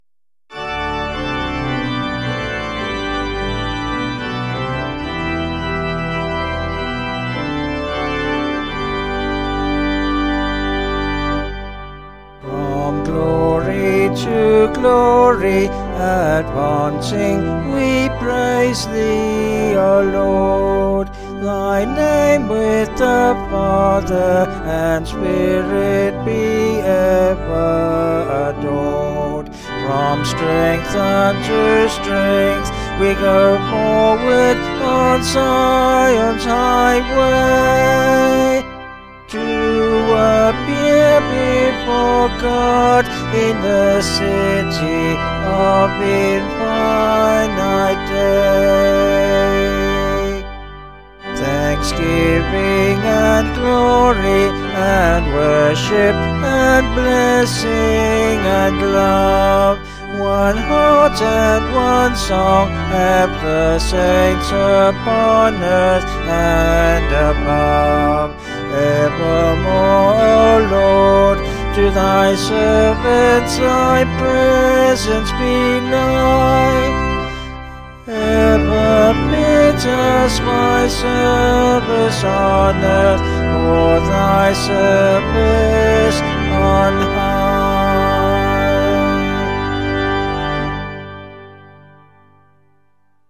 Vocals and Organ   704.2kb Sung Lyrics